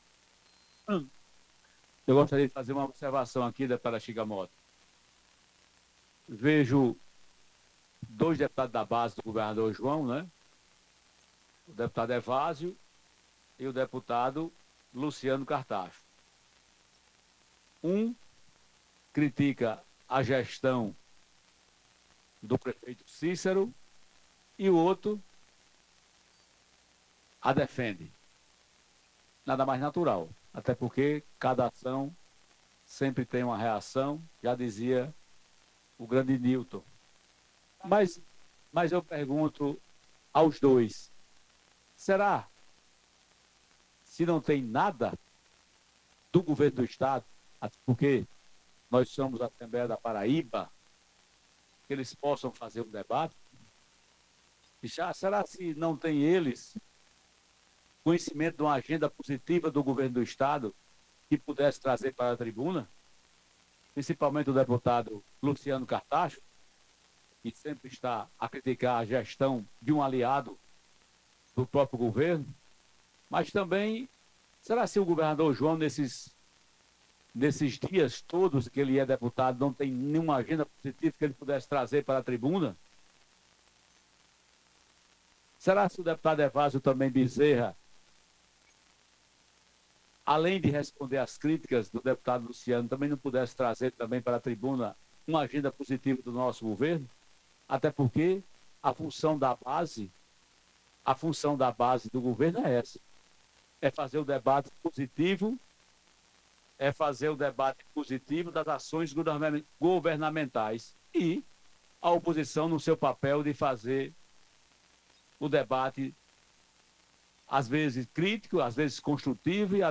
O presidente da Assembleia Legislativa da Paraíba (ALPB), Adriano Galdino (Republicanos), voltou a criticar o posicionamento dos deputados Luciano Cartaxo (PT) e Hervázio Bezerra (PSB), que na sessão desta terça-feira (28) voltaram a usar a tribuna da Casa para criticar (no caso do petista) e defender (no caso do socialista) a gestão do prefeito Cícero Lucena (PP).